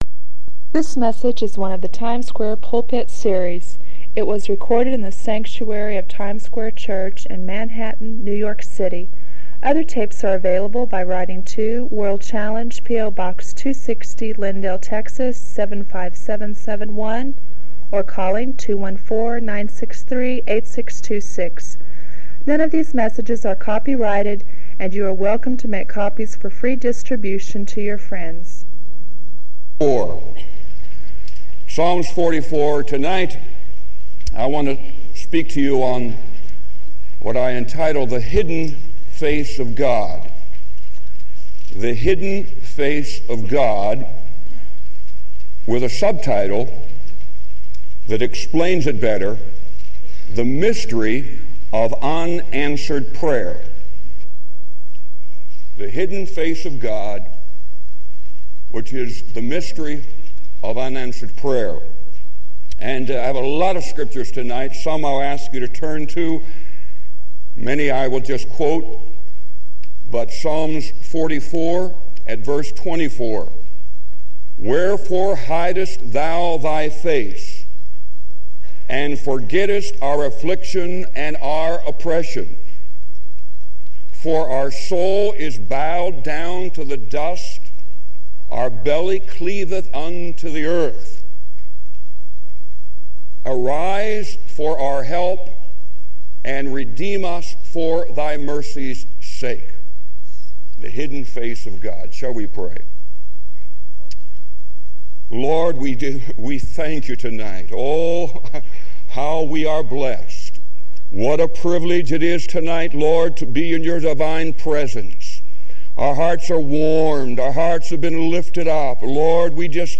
This sermon offers profound insight into the nature of prayer and God's loving discipline.